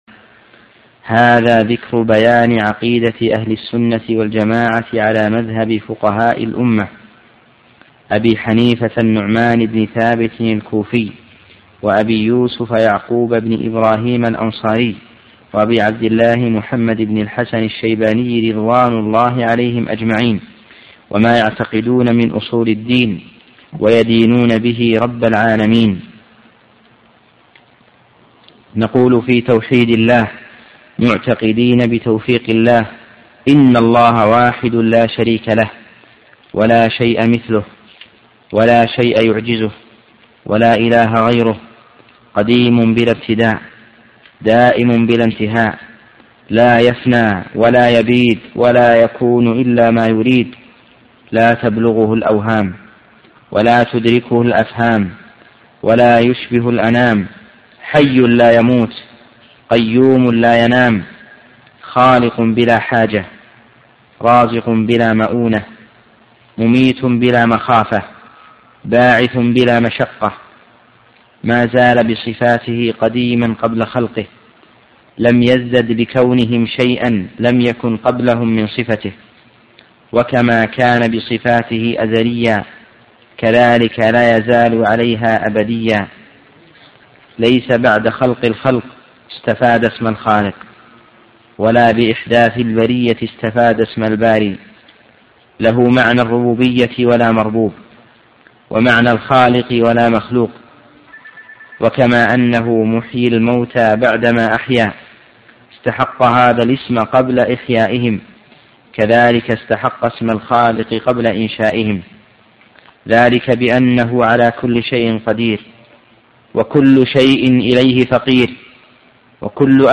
الرئيسية الكتب المسموعة [ قسم العقيدة ] > العقيدة الطحاوية .